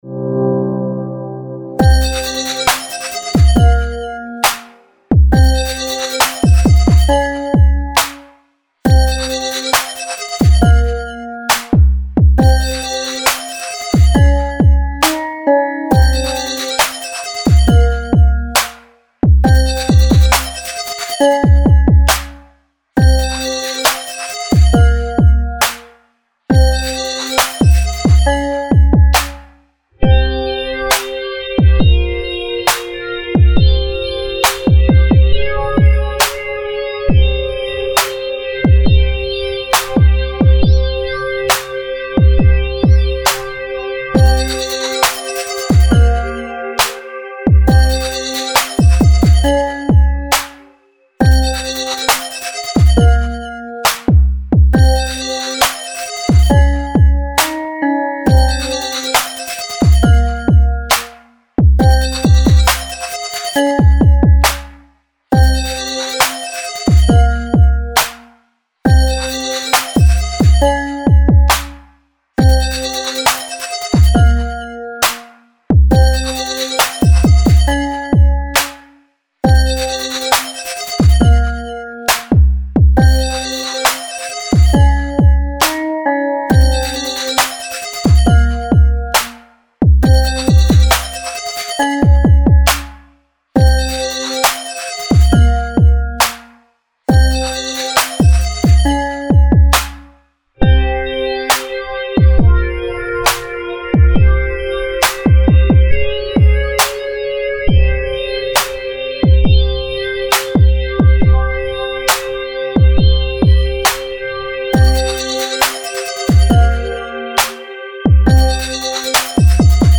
BGM EDM ロング 民族